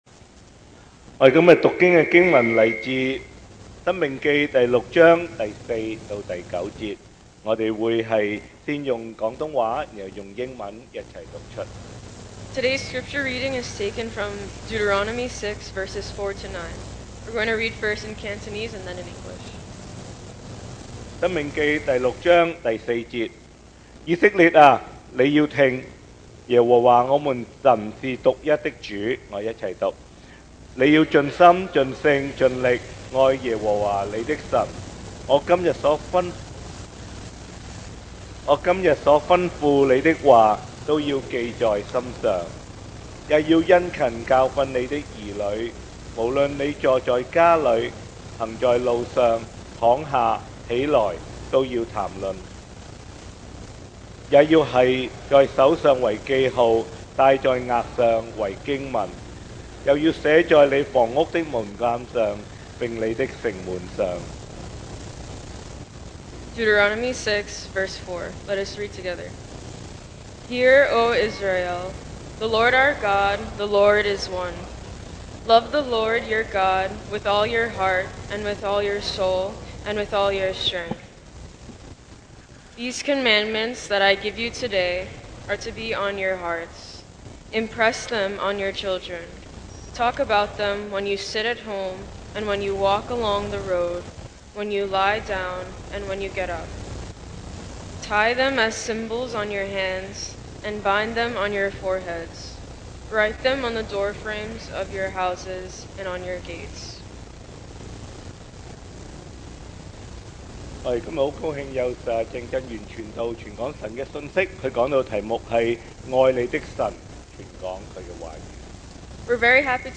Series: 2024 sermon audios
Passage: Deuteronomy 6:4-9 Service Type: Sunday Morning